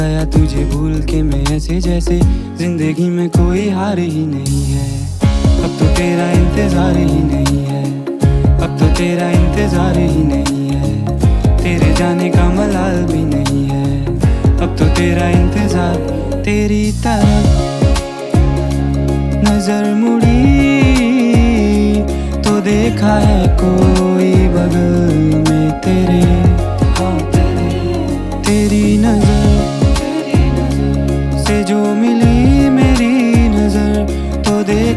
Indian Pop
Жанр: Поп музыка